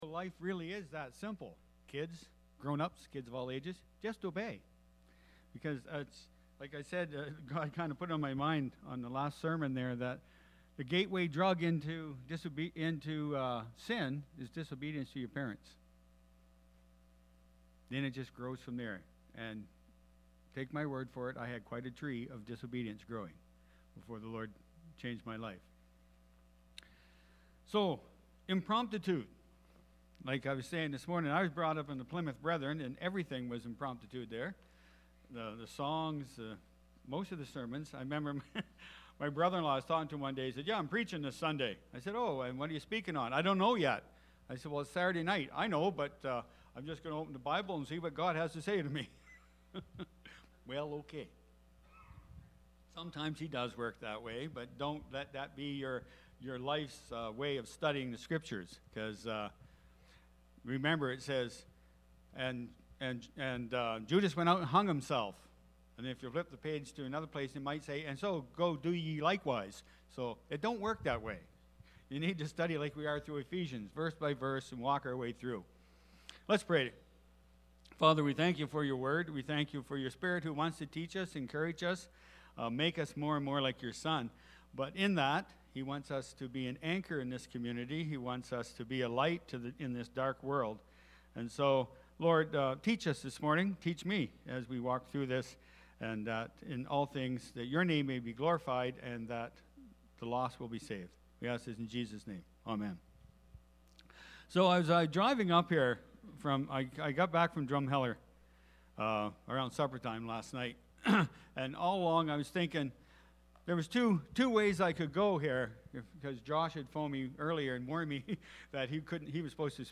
May-7-2023-sermon-audio.mp3